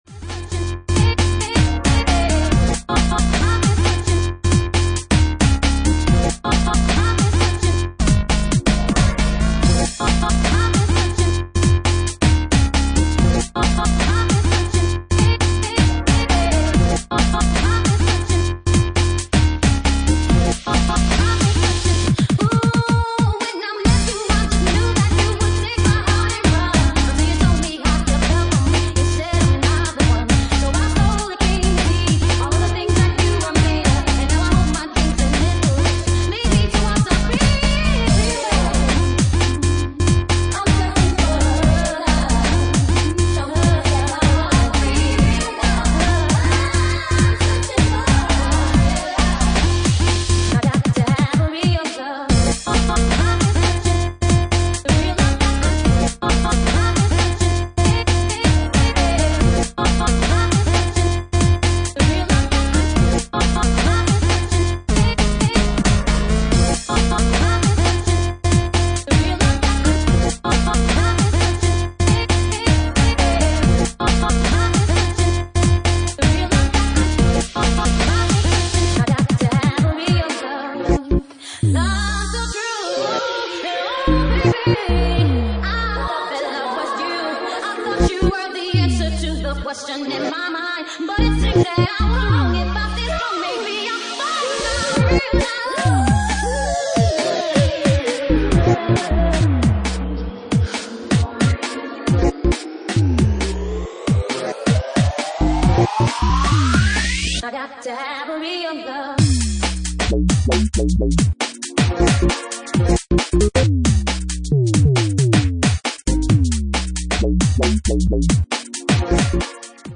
Genre:4x4 / Garage
4x4 / Garage at 68 bpm
BOUNCY UKG, CHECK THE SUB BASS!